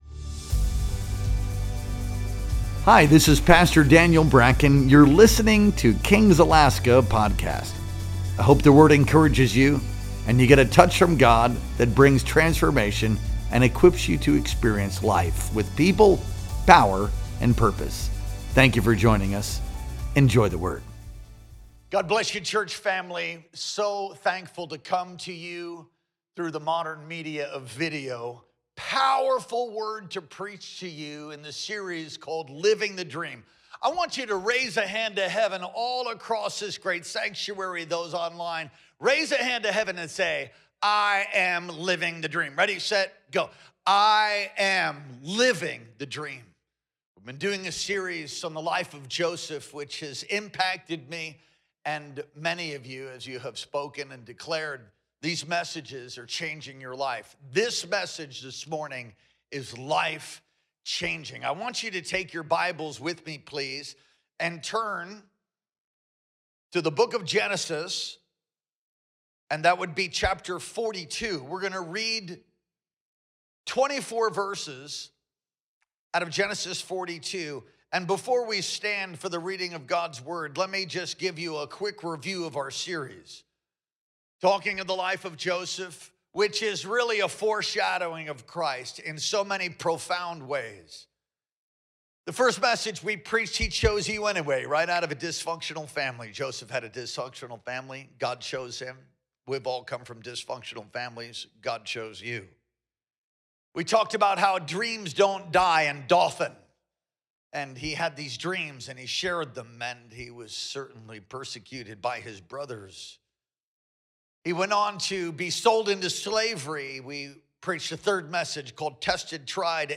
Our Sunday Worship Experience streamed live on May 25th, 2025.